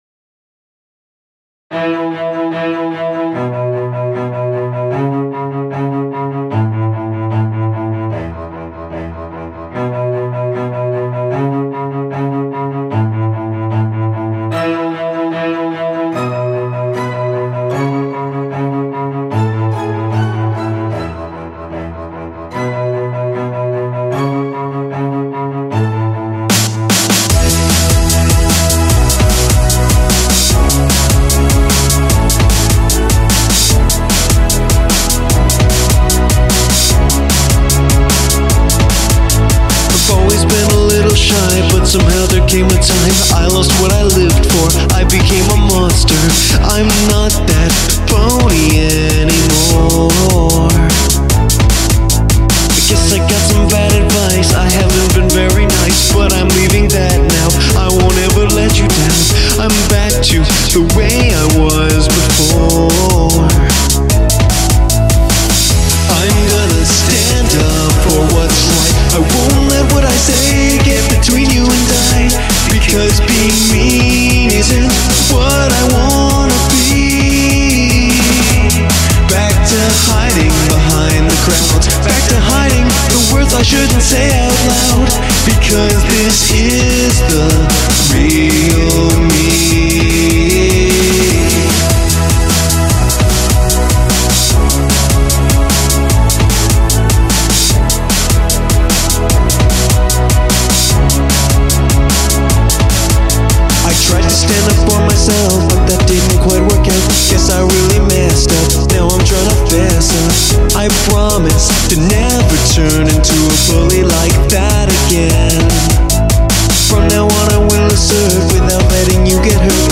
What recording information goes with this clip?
It's not professional sounding, it's cheesy, I know.